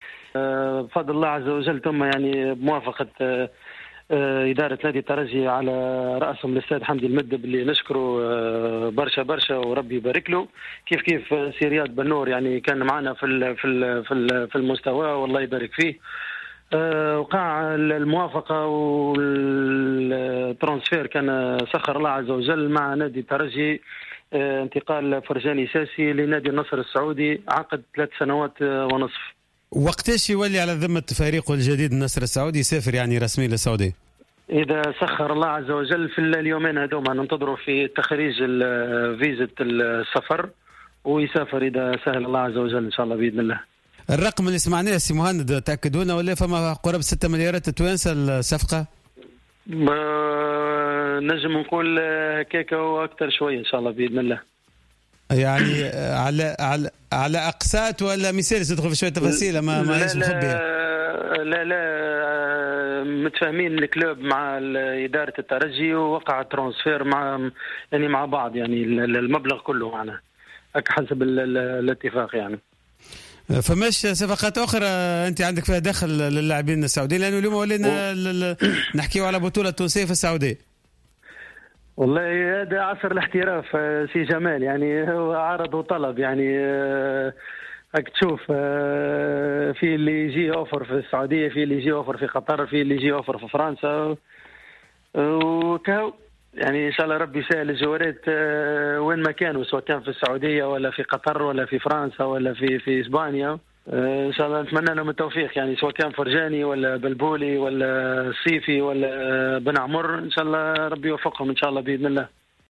مداخلة في حصة راديو سبور